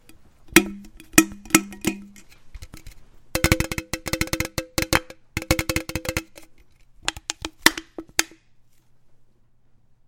苏打水" 苏打水倒
描述：将一罐苏打水倒入玻璃杯中。 用Tascam DR40录制。
标签： 铝可以 苏打水 可以 饮料 苏打可以 流行 浇注苏打水 饮料 倾倒 食品 钠倾 易拉罐 浇注
声道立体声